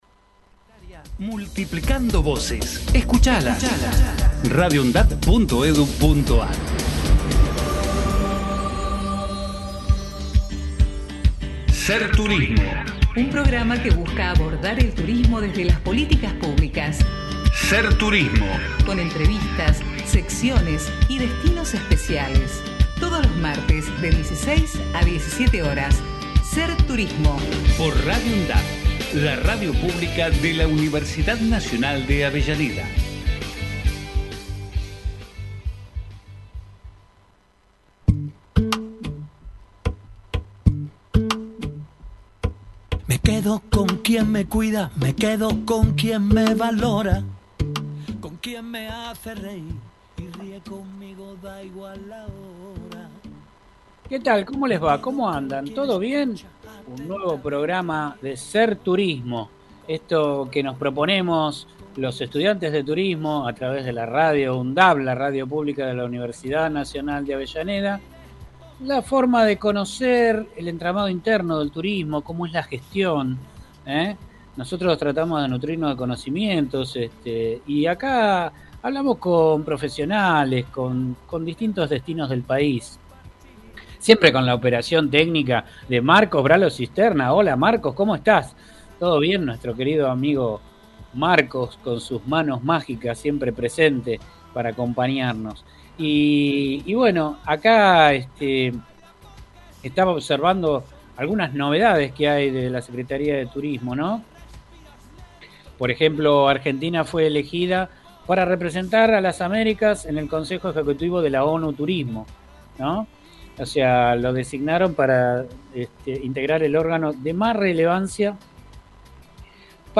Con entrevistas, secciones y destinos especiales, todos los martes de 16 a 17 horas Integrantes